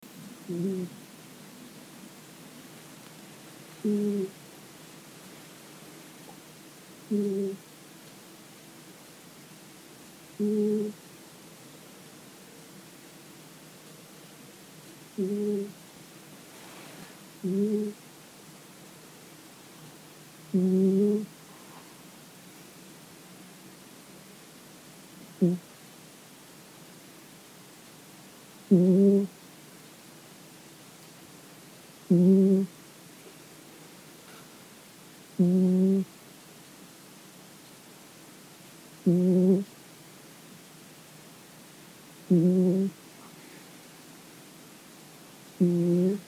Snoring Cat Téléchargement d'Effet Sonore
Snoring Cat Bouton sonore